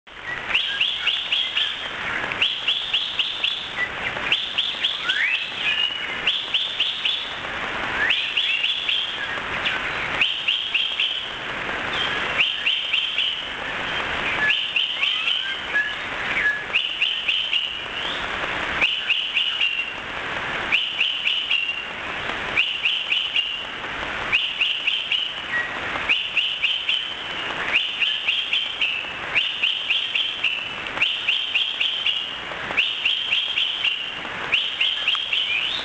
Philippine Hawk-Cuckoo
Cuculus pectoralis
PhilHawkCuckooSubic.mp3